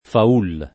Faul [ fa 2 l ] top. (a Viterbo)